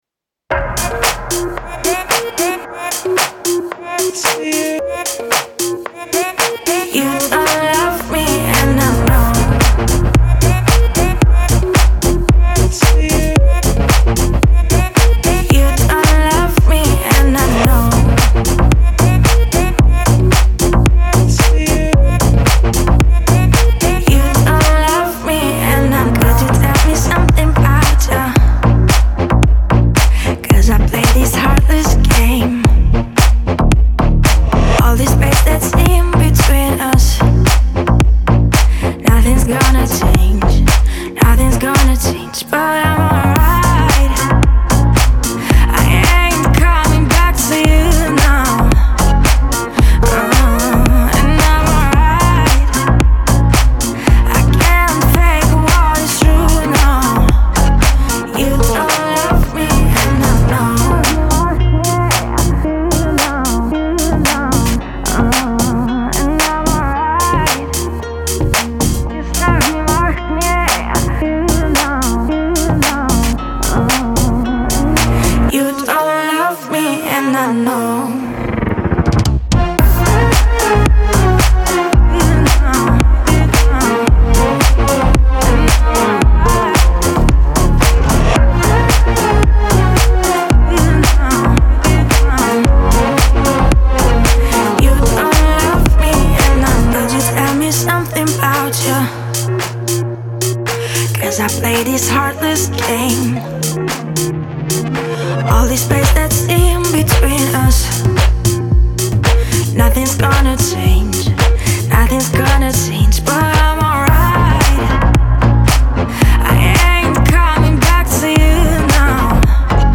это энергичная клубная композиция в жанре EDM